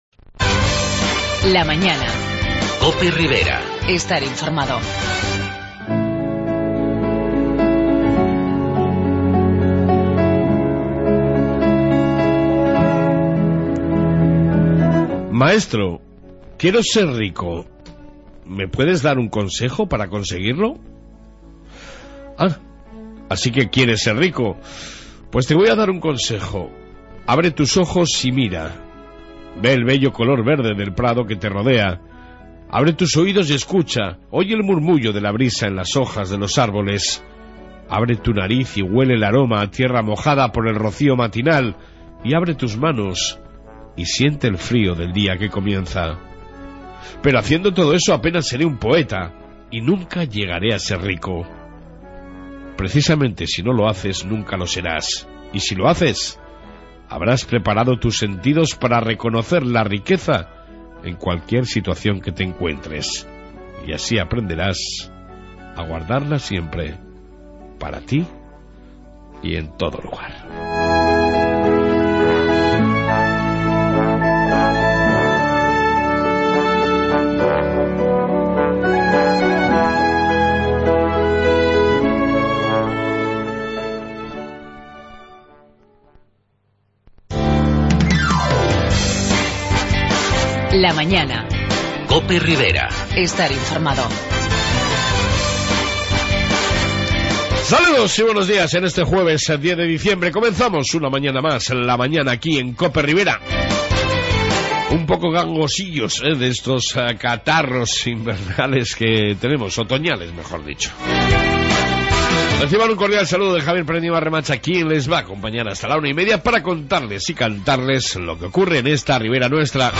AUDIO: Reflexión Diaría, Informe policía Municipal y entrevista